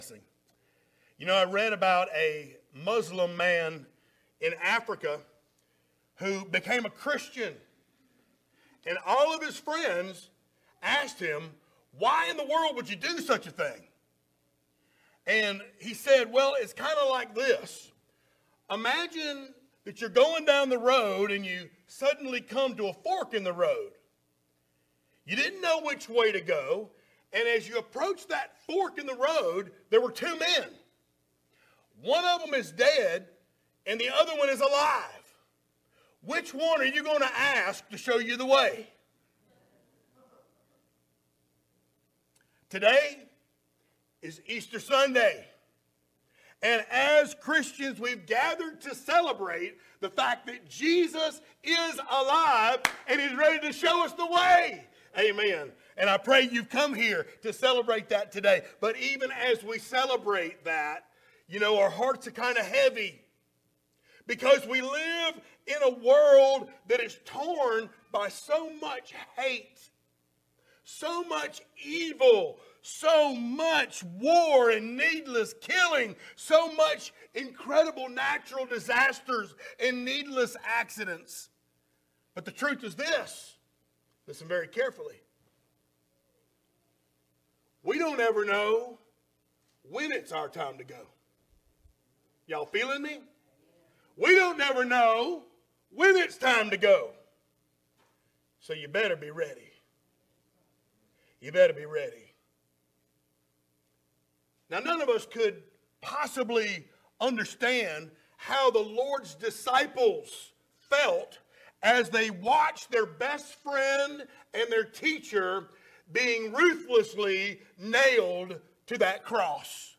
Series: sermons
1 Corinthians 15:1-11 Service Type: Sunday Morning Download Files Notes Topics